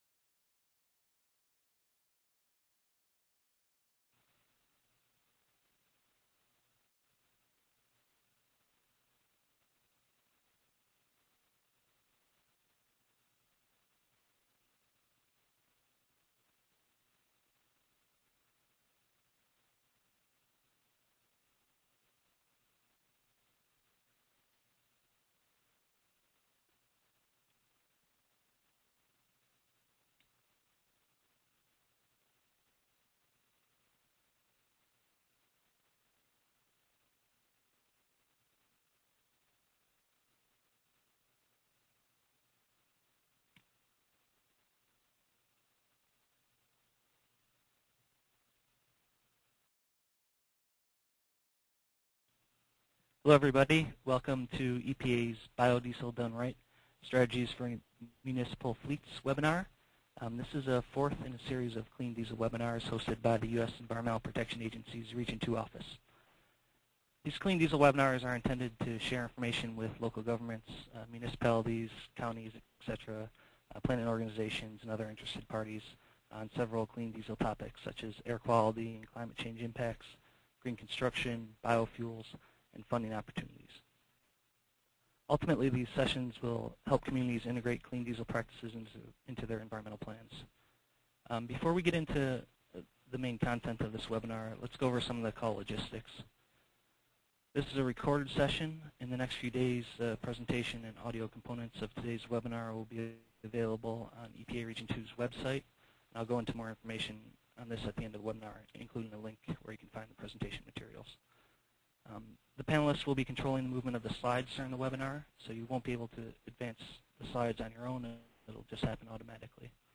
The webinars will emphasize peer exchange, presentations from experts, and use of decision support tools.
EPA Region 2 is hosting a series of free web-based information sessions and discussions with experts pertaining to clean diesel practices and strategies. The sessions are intended to share information with municipalities, municipal planning organizations and other interested parties on several clean diesel topics such as air quality and climate change impacts, green construction, biofuels, and funding opportunities.